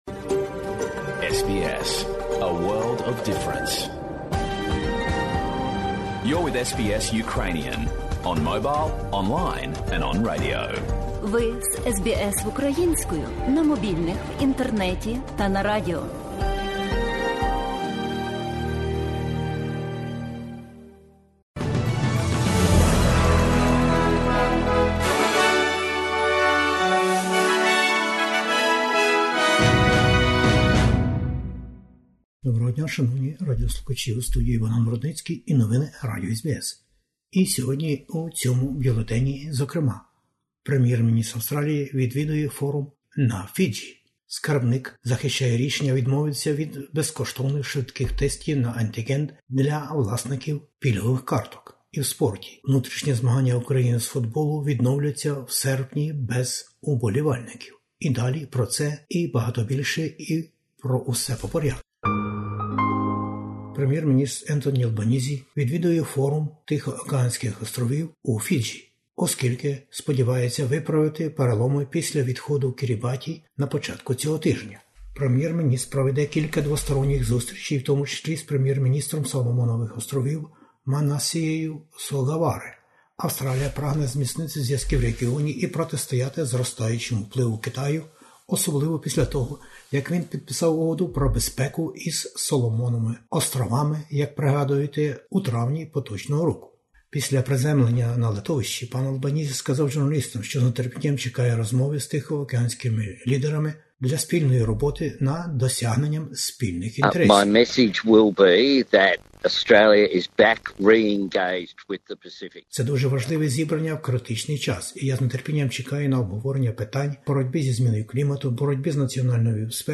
SBS Ukrainian, 3 pm FM, TV Ch. 38 and 302, every Thursday Source: SBS